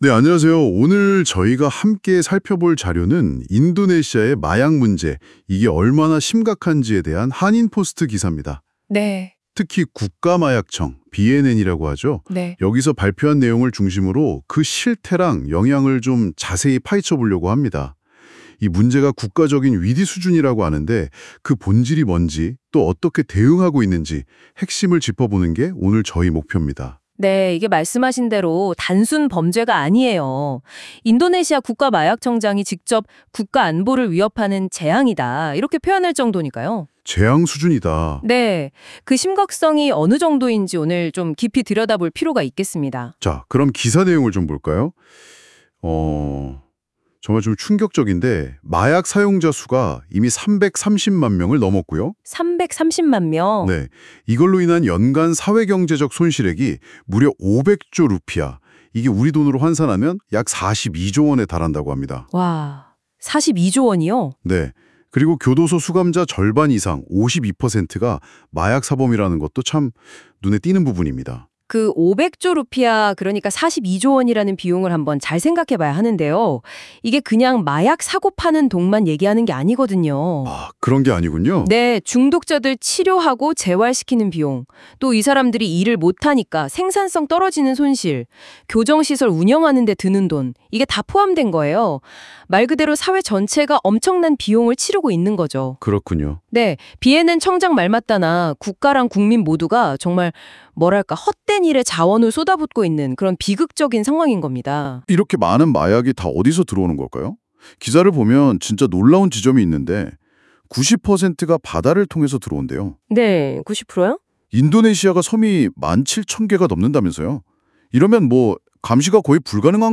• 라디오 한인포스트 듣기 (AI방송)